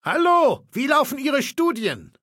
Datei:Maleold01 ms06 hello 000681b6.ogg